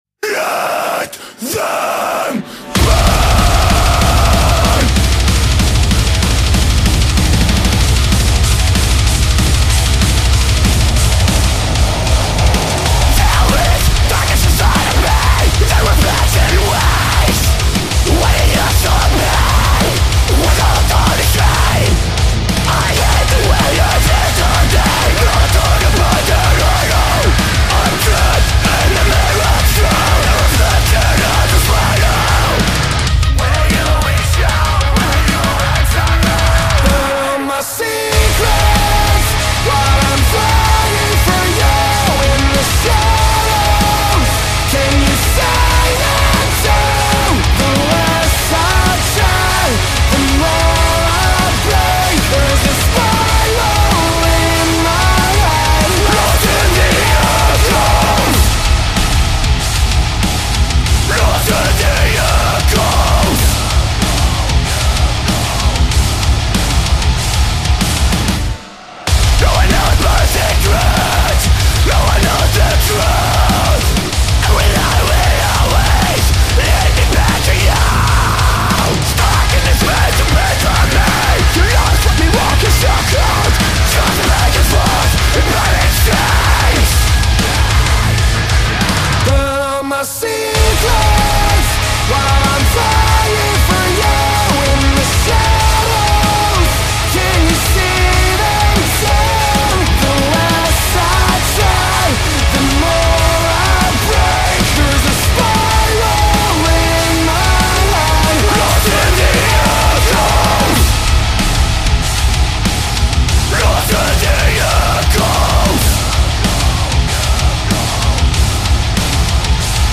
metalcore